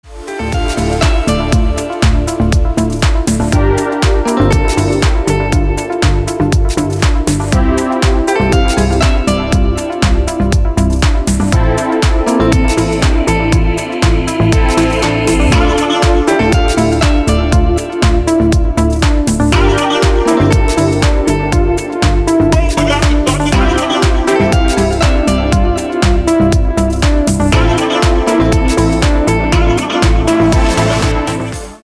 • Качество: 320, Stereo
мужской голос
deep house
мелодичные
dance
Electronic
EDM
электронная музыка
спокойные
nu disco